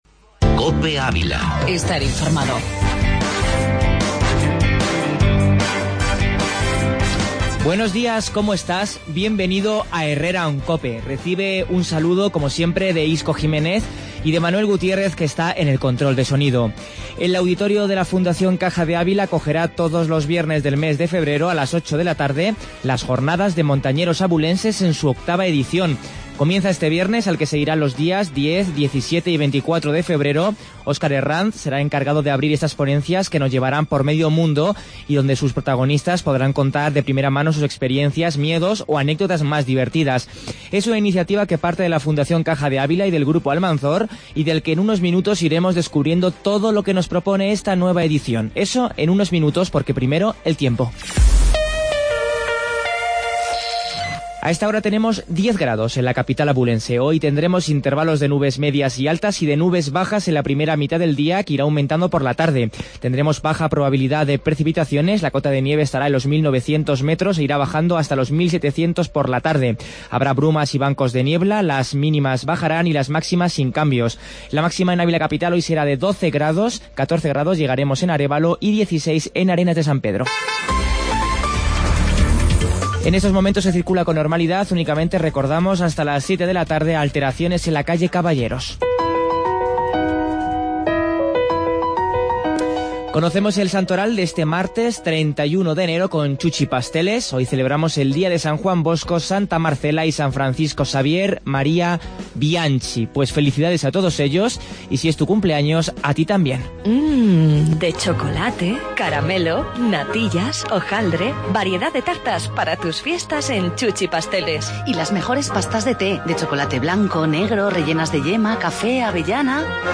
AUDIO: Entrevista Montañeros